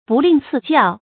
不吝赐教 bù lìn cì jiào 成语解释 吝：吝惜；赐：赏予；教：教导，教诲。